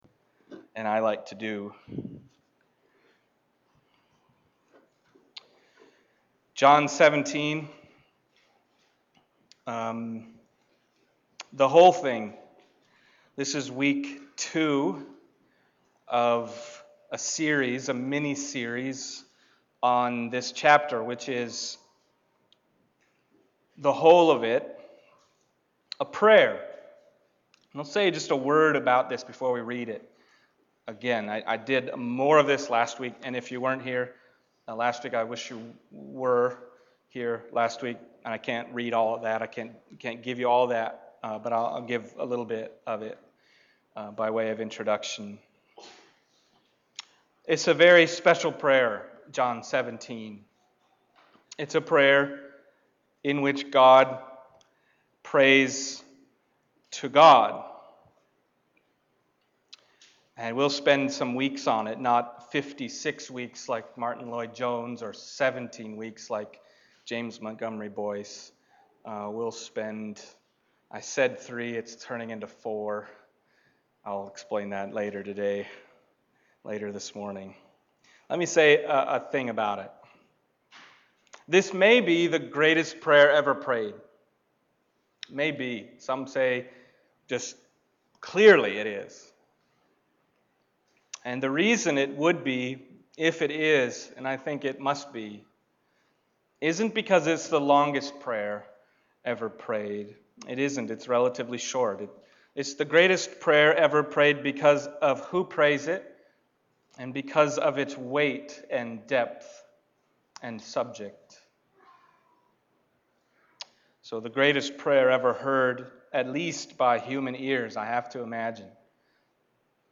John Passage: John 17:6-19 Service Type: Sunday Morning John 17:6-19 « And Then God Prayed to God…